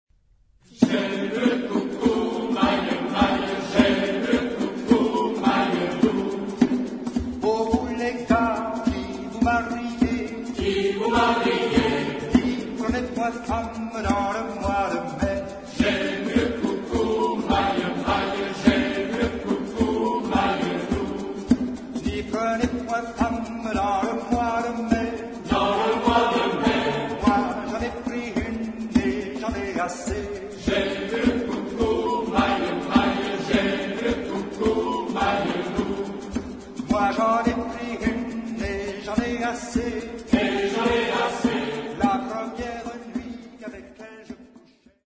Genre-Style-Forme : Populaire ; Profane
Caractère de la pièce : joyeux
Type de choeur : SAH OU SMA OU TBarB  (3 voix mixtes OU égales )
Tonalité : la mineur